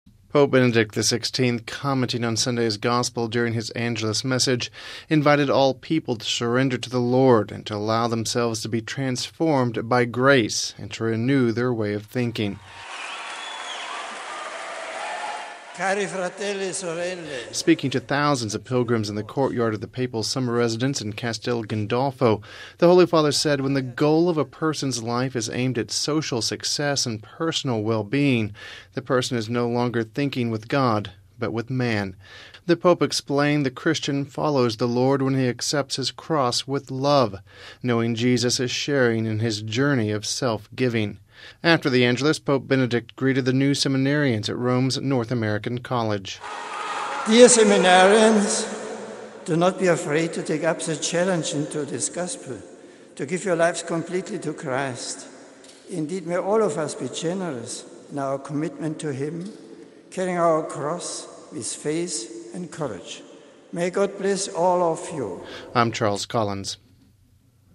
Speaking to thousands of pilgrims in the courtyard of the Papal summer residence in Castel Gandolfo, the Holy Father said when the goal of a person’s life is aimed at social success and personal well-being, the person is no longer thinking with God, but with man.